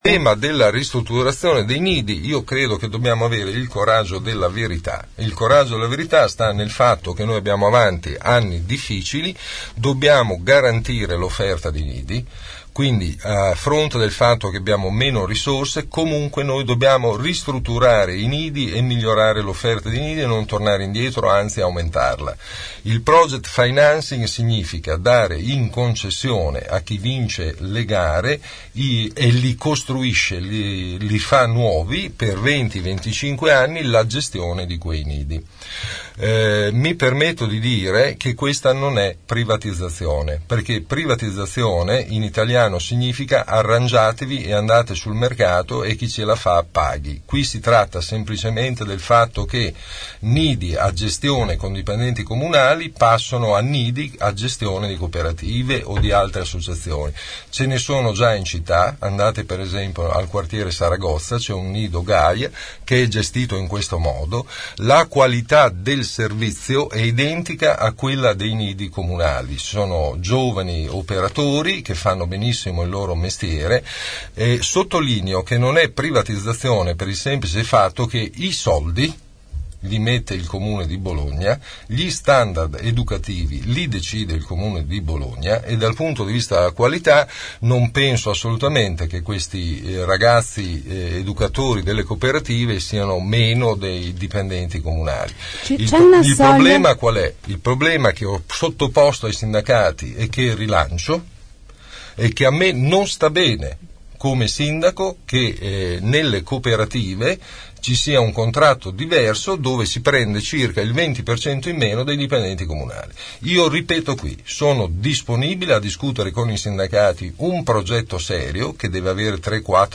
Il candidato del centro sinistra ospite dei nostri studi ha risposto alle nostre domande e a quelle degli ascoltatori.
Un’educatrice ha chiesto qual è la posizione di Merola sul project financing per ristrutturare i nidi comunali.